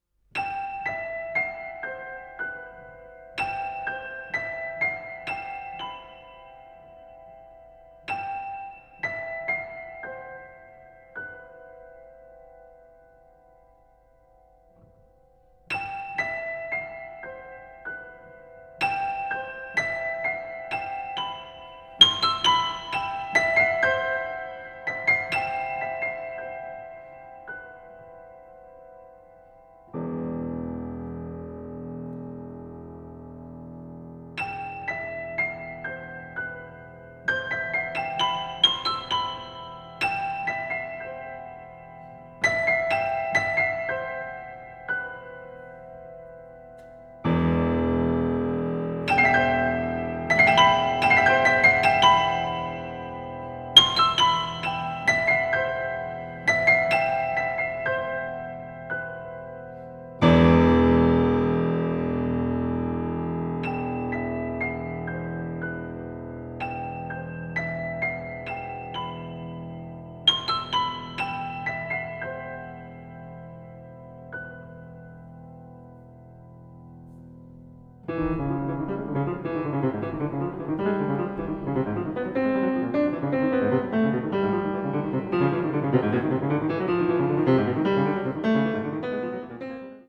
french horn, flugelhorn
violinchello
chamber music   contemporary   contemporary jazz   deep jazz